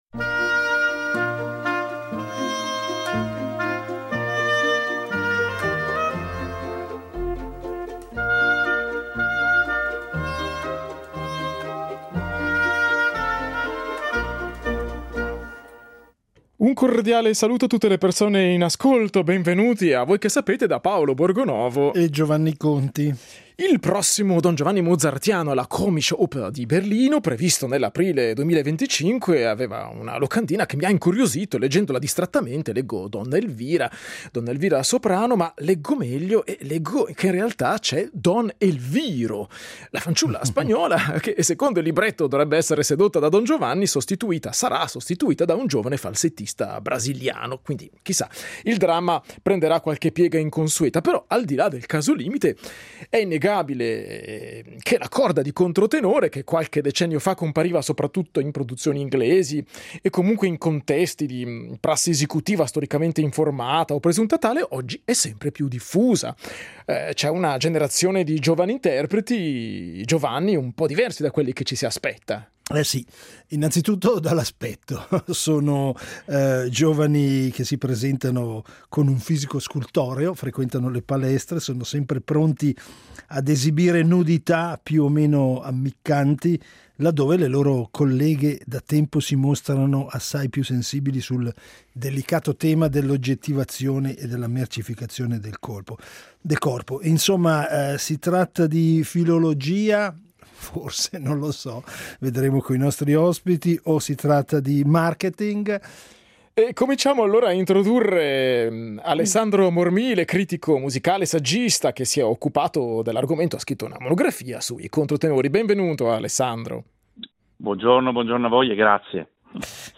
musicologo e studioso di drammaturgia settecentesca